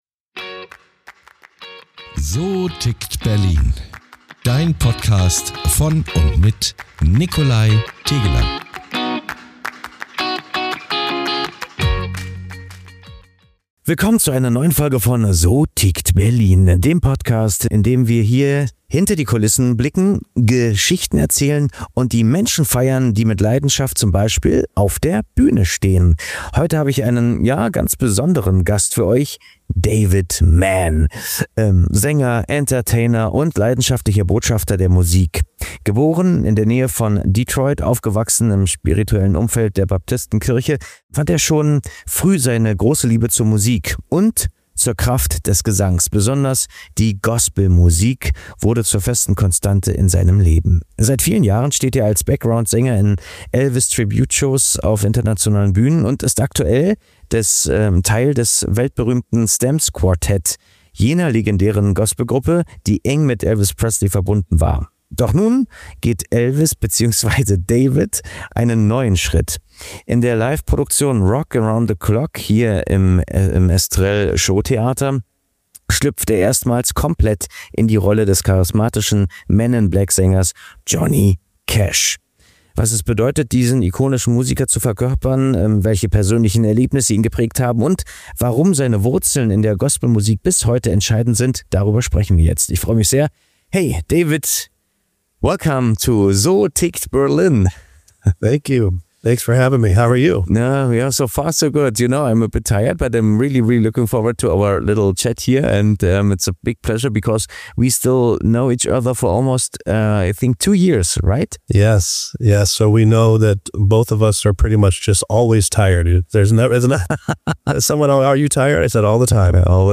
Wir sprechen über seine Karriere, die Magie der Rock’n’Roll-Ära und warum es für ihn ein echtes Privileg ist, diese Musik lebendig zu halten. Ein Gespräch voller Leidenschaft, Musikgeschichten und einem Hauch Nostalgie!